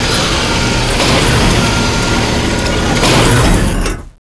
Index of /cstrike/sound/turret
tu_retract.wav